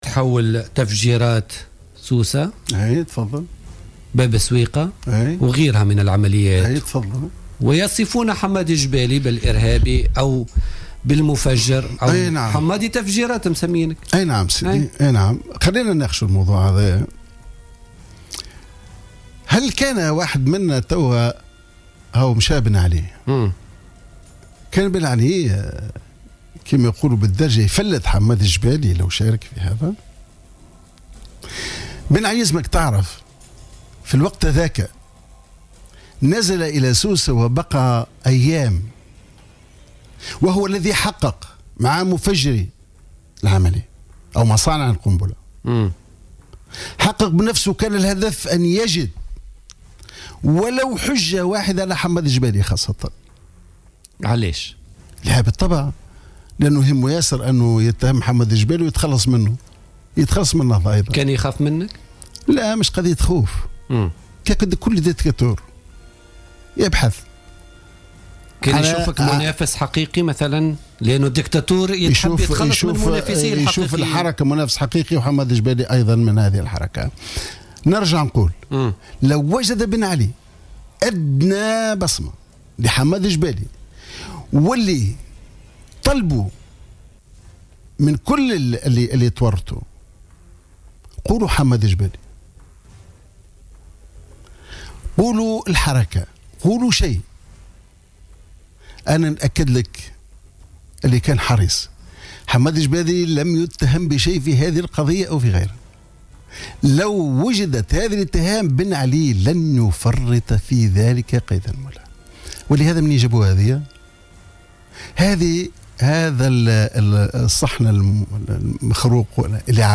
علّق الأمين العام السابق لحركة النهضة حمادي الجبالي ضيف برنامج "بوليتيكا" اليوم على من وصفوه ب"حمادي تفجيرات".